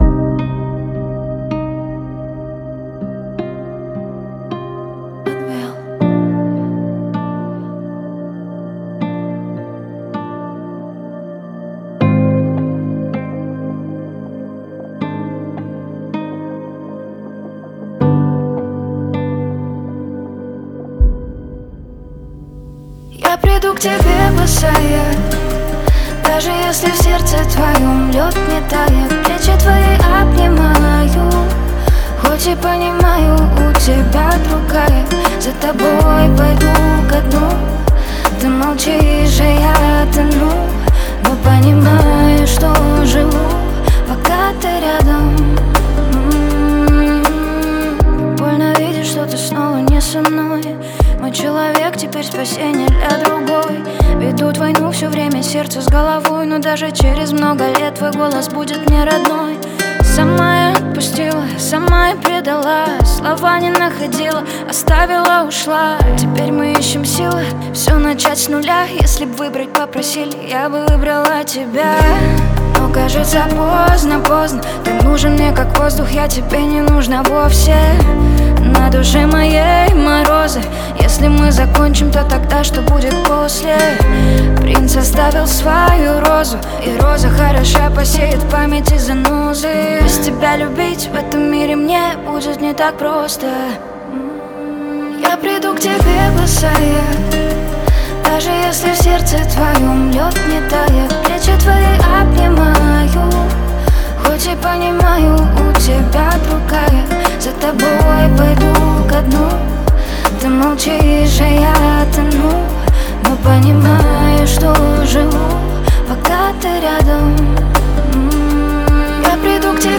грустные песни , лиричные песни Размер файла